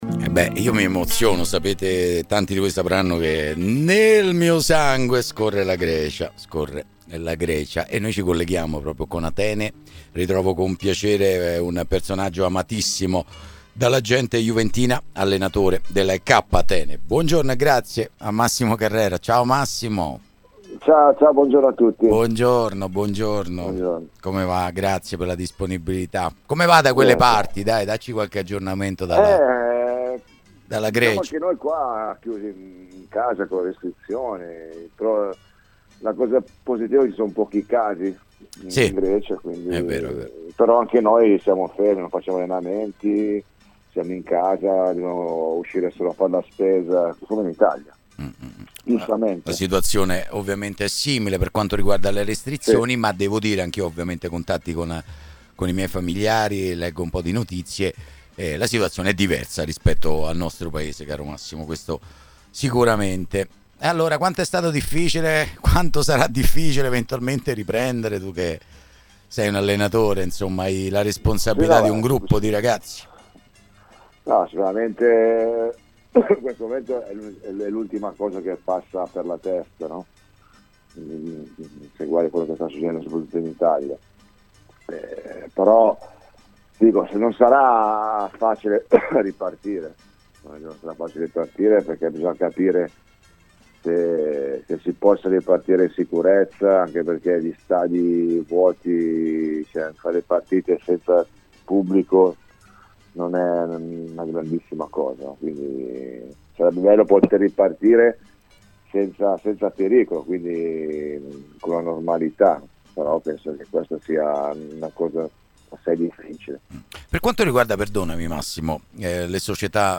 Massimo Carrera ( Allenatore ) ospite a stile Juventus.